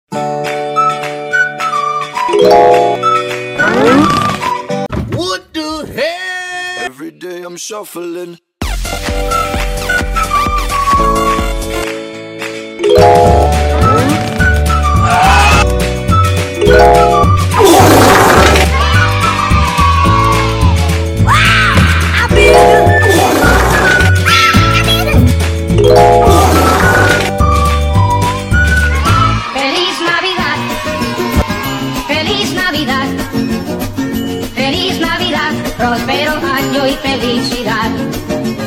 Water drops